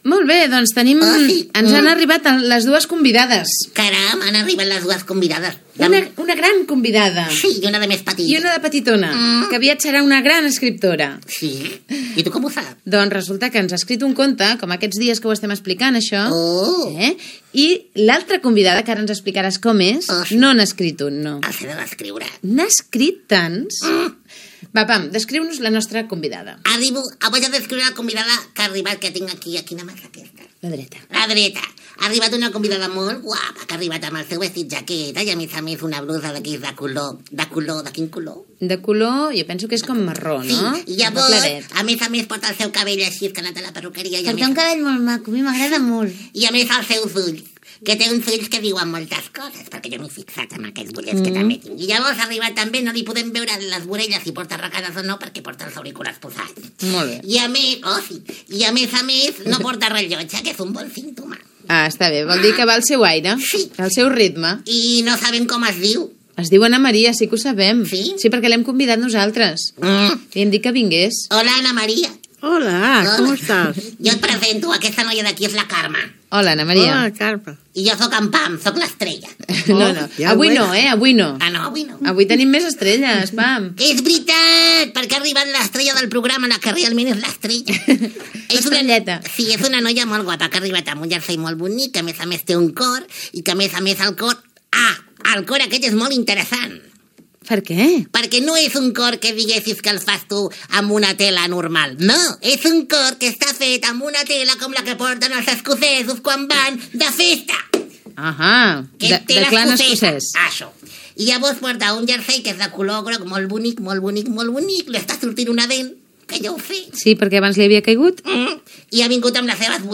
Presentació i entrevista a l'escriptora Ana María Matute que ha publicat un recull de contes per a infants
Gènere radiofònic Infantil-juvenil